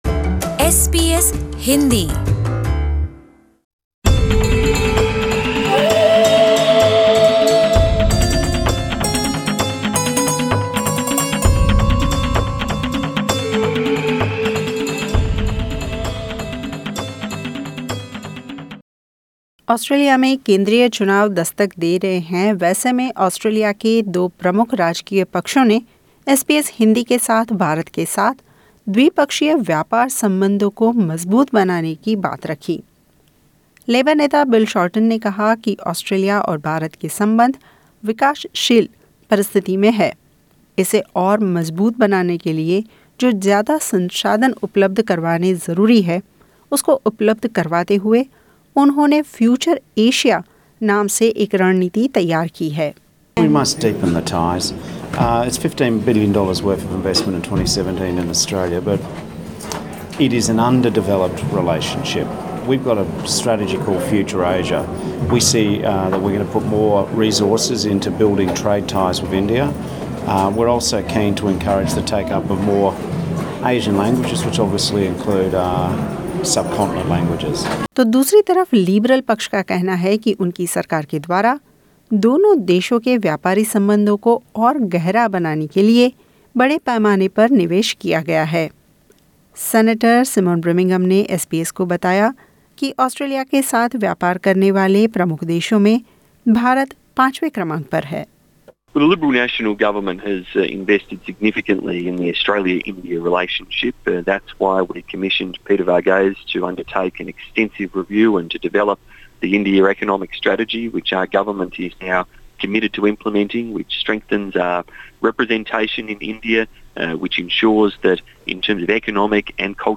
SBS Hindi recently caught up with Labor Leader Bill Shorten and Australia's Minister for Trade, Investment and Tourism, Simon Birmingham, who emphasised on deepening ties with India.